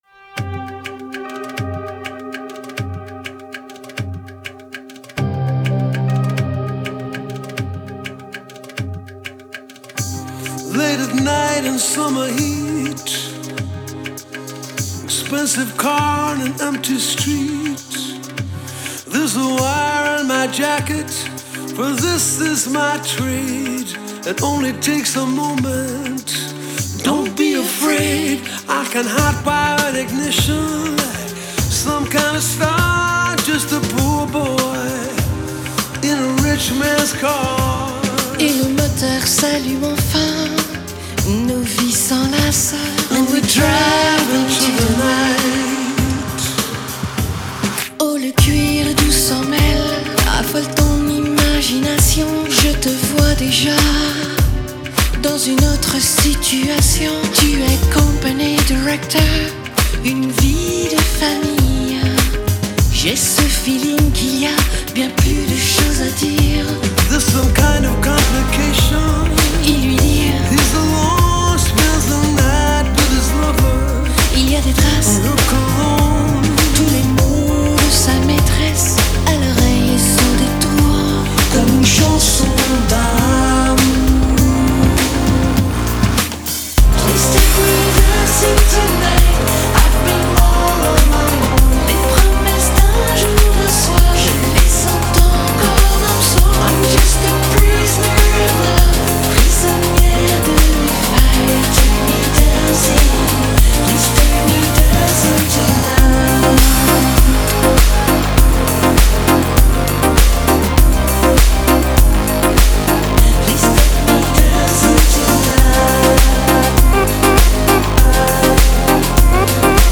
Genre: New Wave